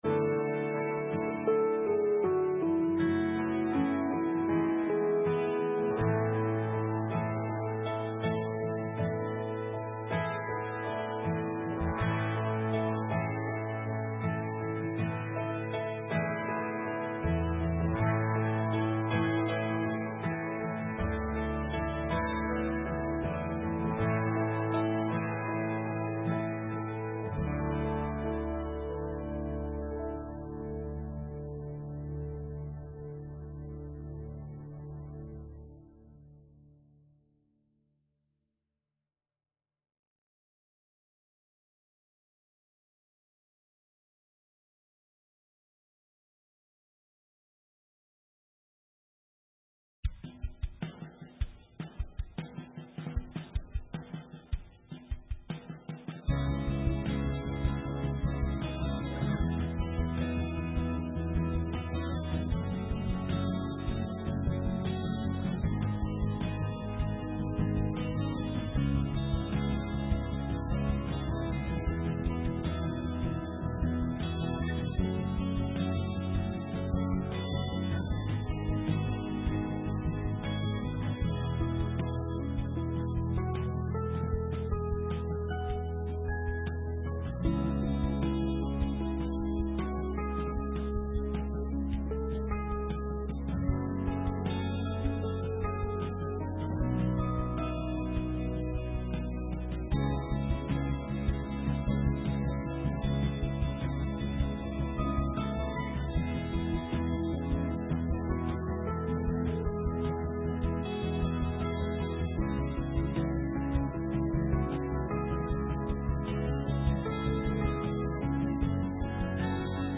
Sermon:God’s Move from Night to Morning - St. Matthews United Methodist Church
Gods-Move-from-Night-to-Morning-Sunday-Worship-September-5-2021.mp3